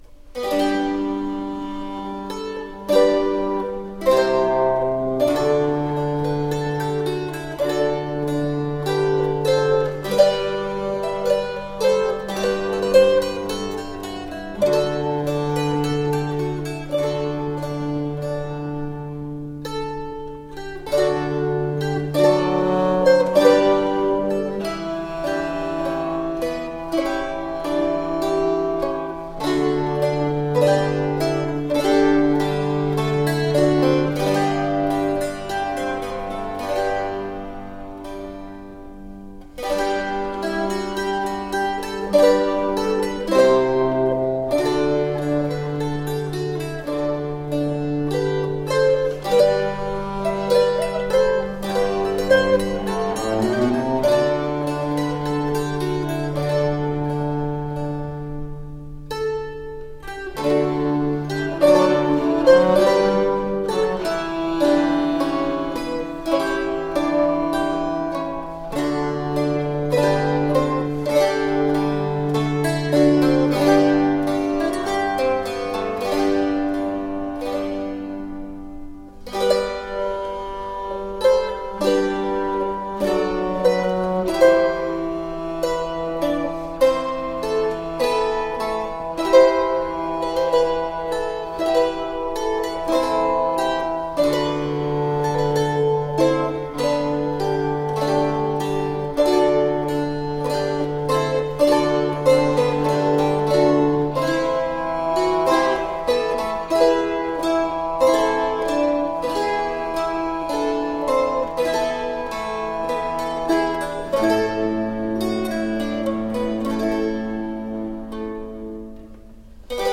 Renaissance lute.
Cittern with a bass
Classical, Renaissance, Instrumental